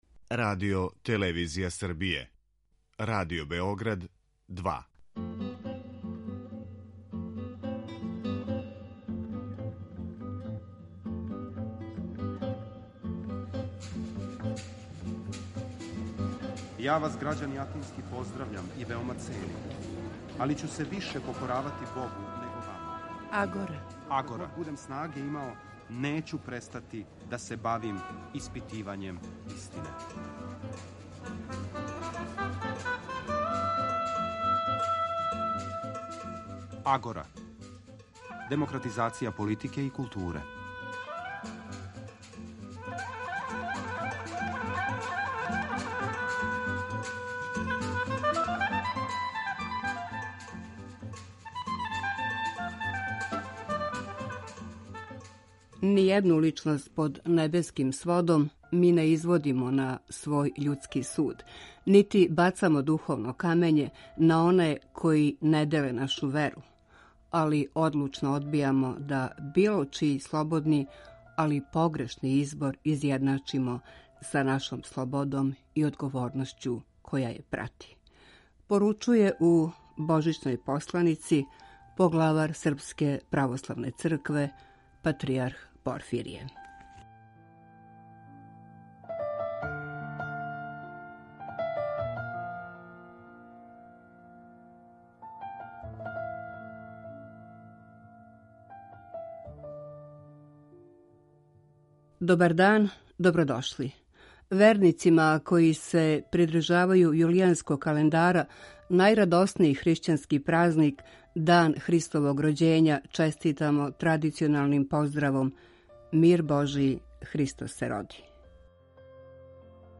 Радио-магазин који анализира феномене из области политичког живота, филозофије, политике и политичке теорије.
O дану Христовог рођења, разумевању и очувању православне вере, о божанској и световној љубави, месту цркве у савременом добу, праштању и спознавању себе самог разговараћемо са господином Иларионом, викарним епископом патријарха српског, са титулом епископ новобрдски.